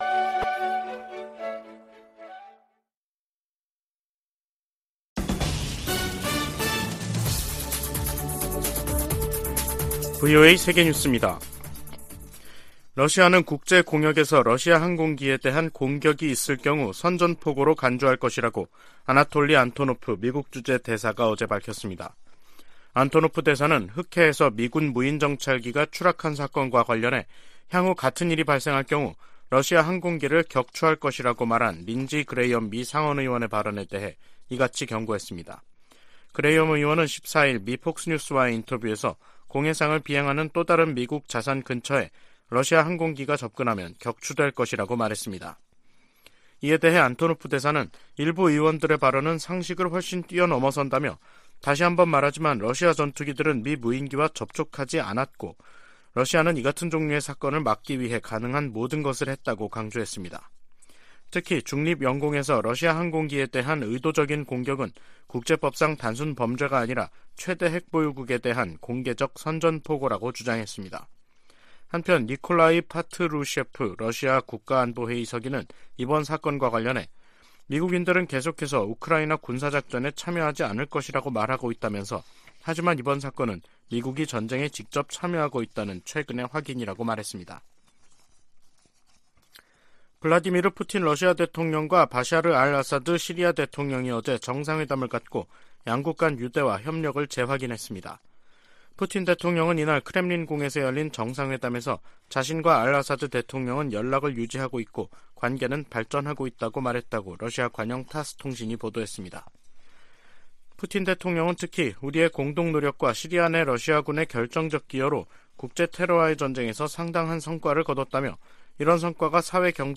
VOA 한국어 간판 뉴스 프로그램 '뉴스 투데이', 2023년 3월 16일 2부 방송입니다. 윤석열 한국 대통령과 기시다 후미오 일본 총리가 정상회담을 통해 북핵과 미사일 위협에 대응한 공조를 강화하기로 했습니다. 북한이 16일 ‘화성-17형’으로 추정되는 대륙간탄도미사일(ICBM)을 발사했습니다. 미국 정부는 북한의 ICBM 발사가 안보리 결의에 위배되고 역내 긴장을 고조시킨다며 강력 규탄했습니다.